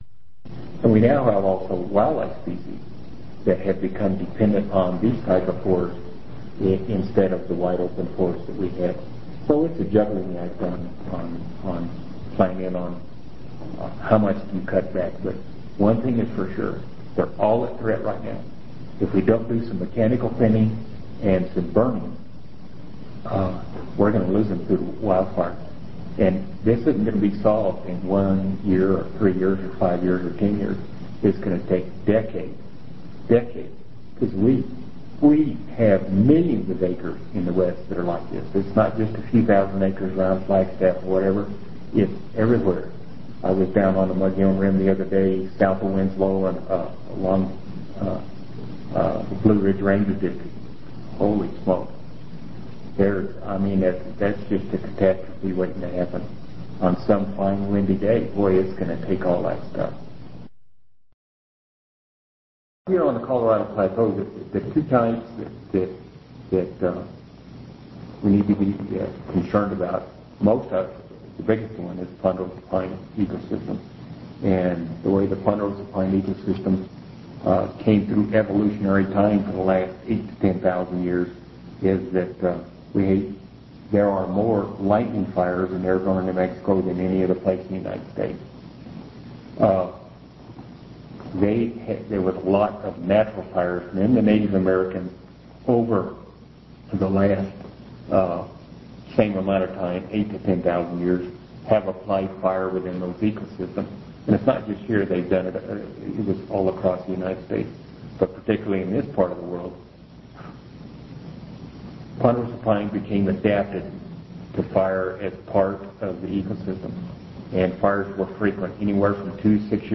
Hear interview excerpts